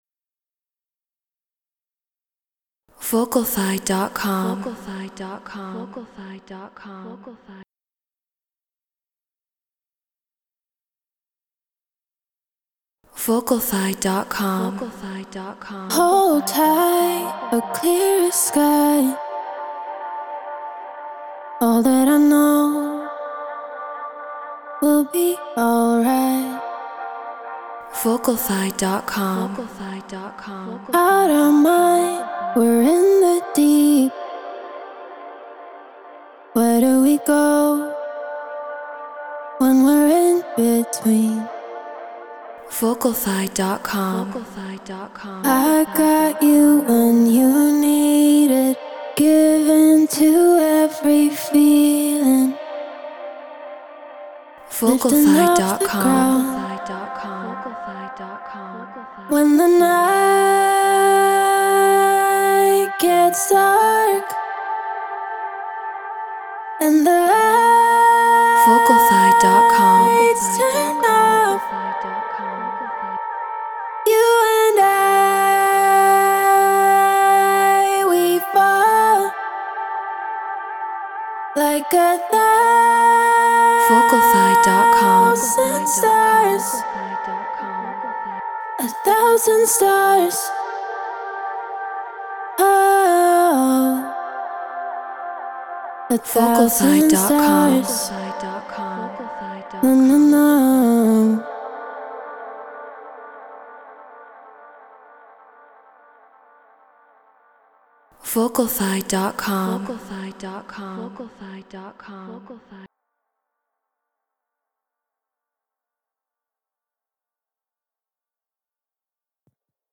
Stutter House 128 BPM Gmin
Shure KSM 44 Apollo Twin X Pro Tools Treated Room